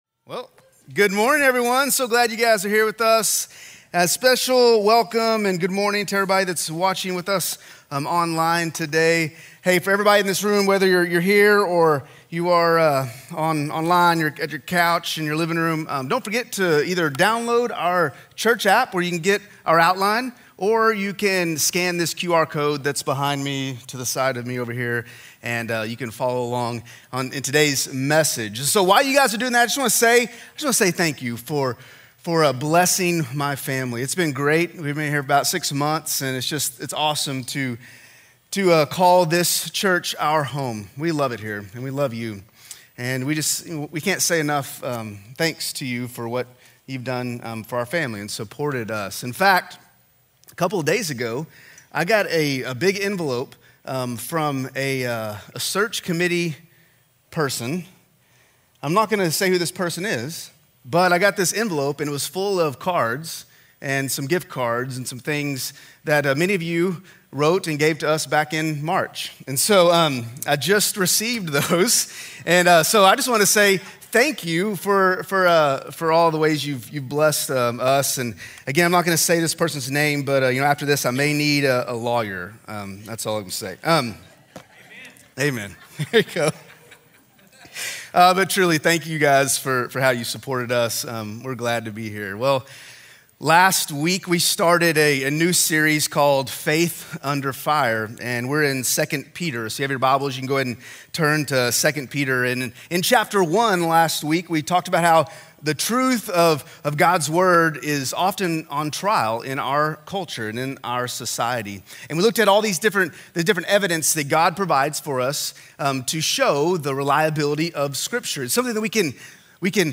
Sermons - Central Baptist Church Owasso